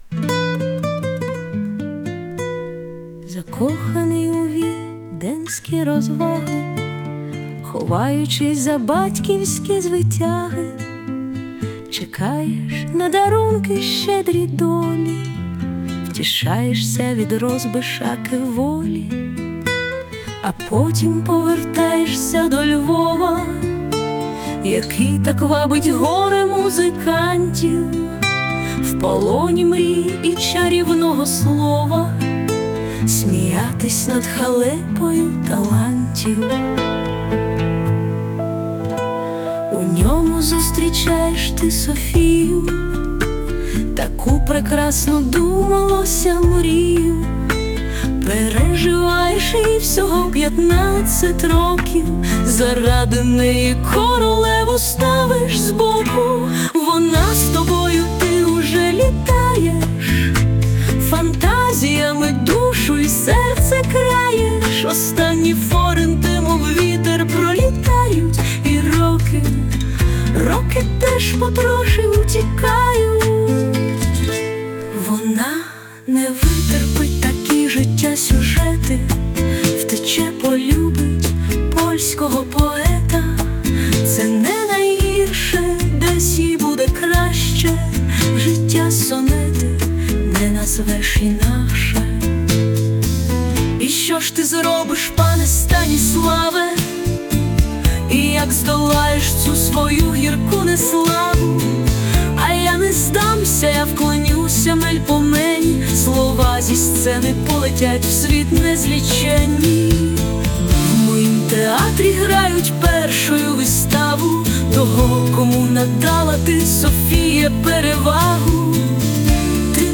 Музичне прочитання з допомогою ШІ